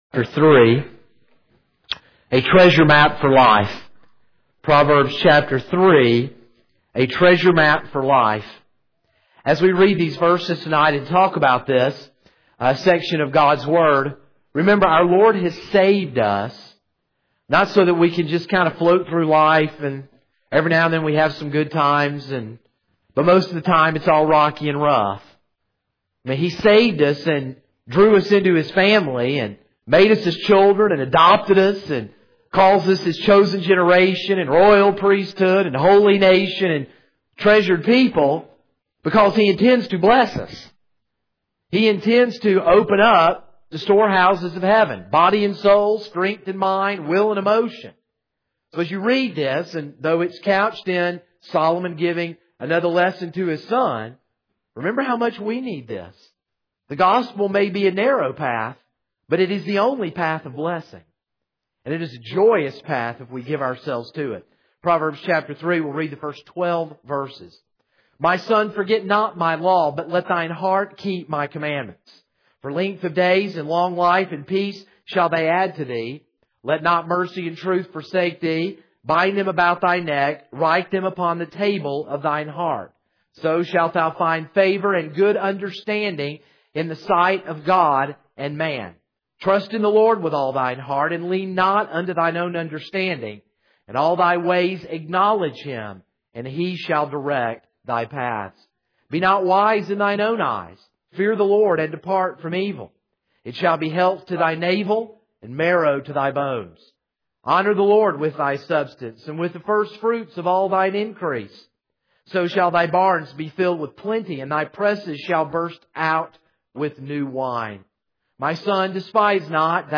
This is a sermon on Proverbs 3:1-12.